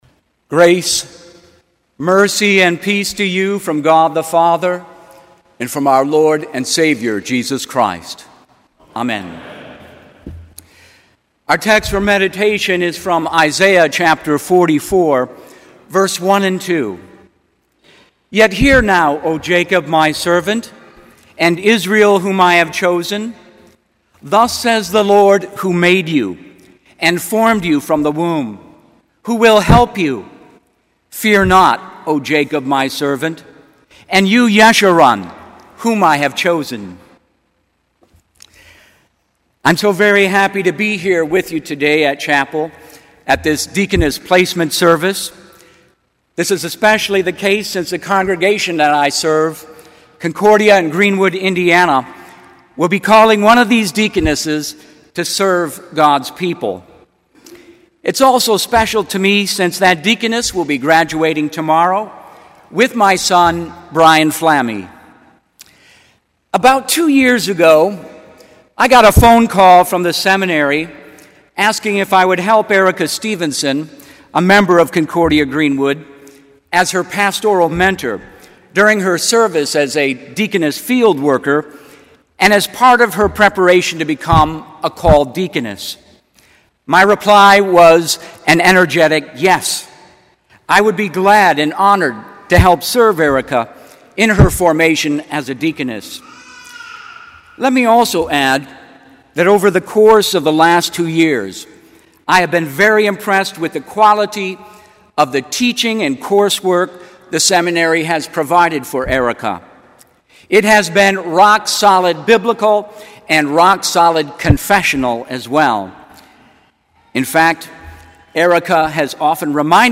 Kramer Chapel Sermon - May 16, 2013